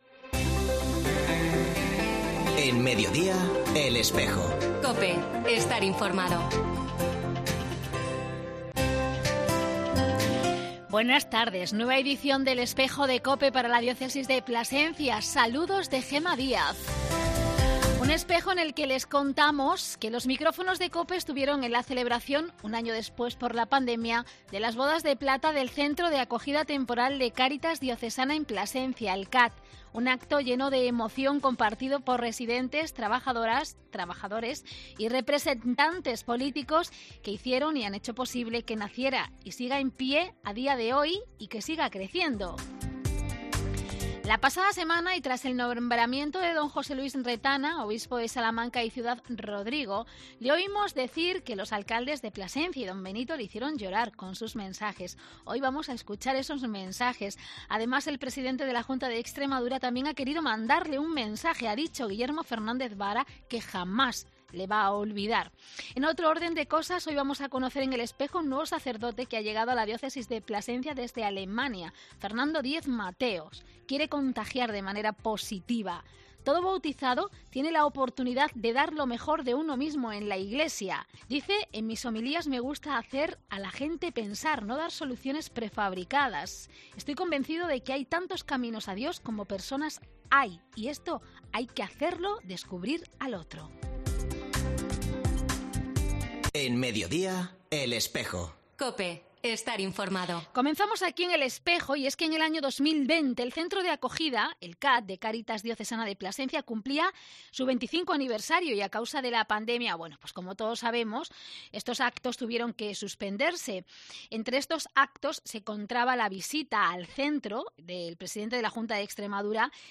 Los micrófonos de Cope, estuvieron en el acto del 25 aniversario del CAT Cáritas Diocesana de Plasencia